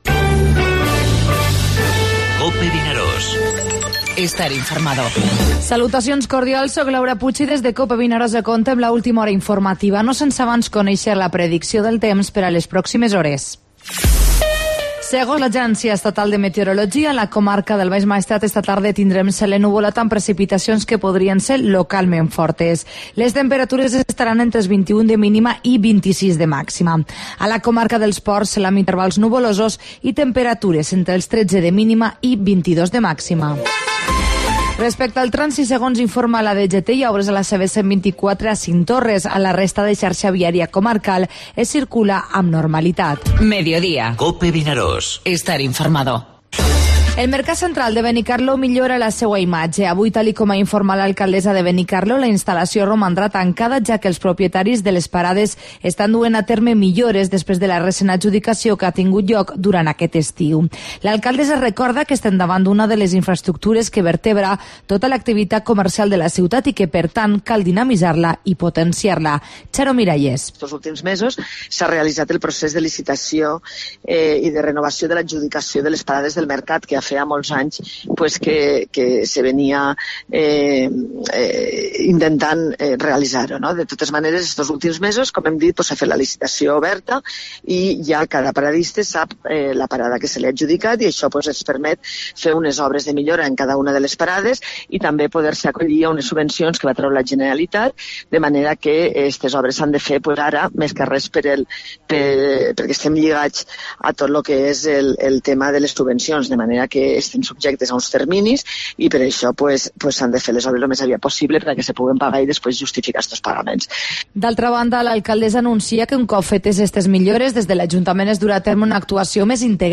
Informativo Mediodía COPE al Maestrat (04/09/2017)